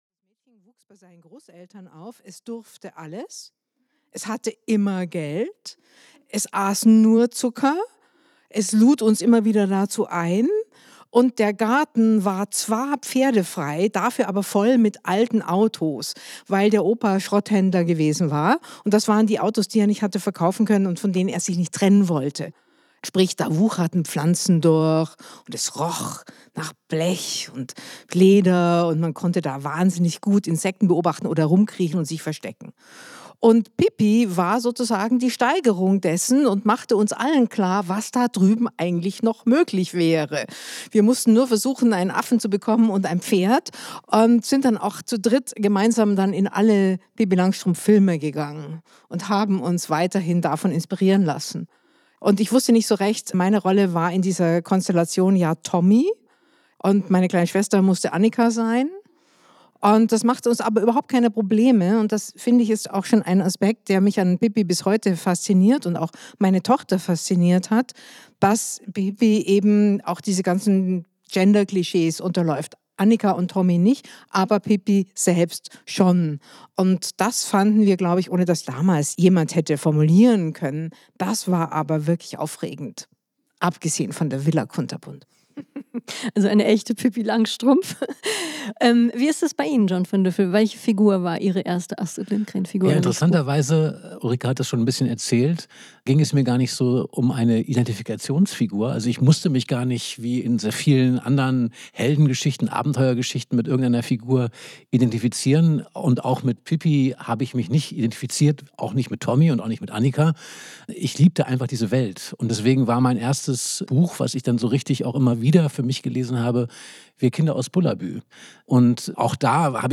An diesem Abend diskutieren die Schriftstellerin Ulrike Draesner und der Dramaturg und Autor John von Düffel, welche Bedeutung Lindgrens Werk heute hat. Im Mittelpunkt werden "Pippi Langstrumpf" stehen und "Die Brüder Löwenherz".
Mitarbeit Interviewte Person: Ulrike Draesner, John von Düffel